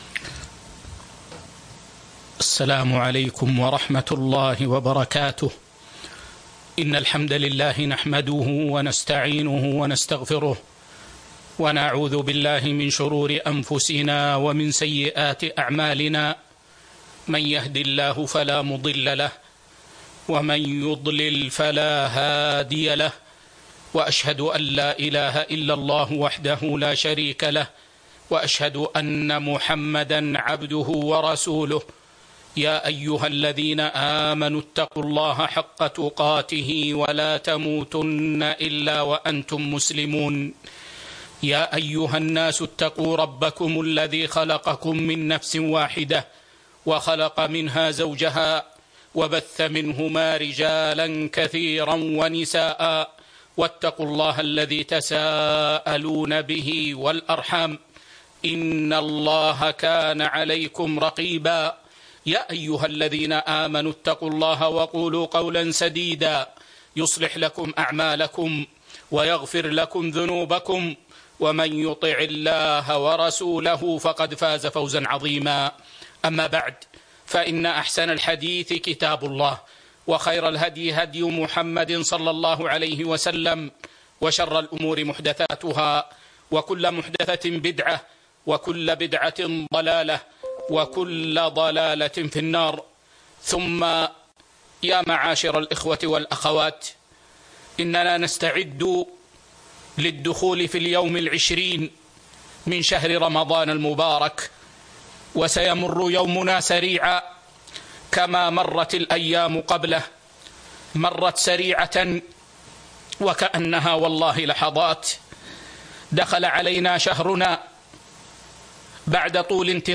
محاضرة جديدة قيمة - وجاءت العشر الأواخر 19 رمضان 1441 هــ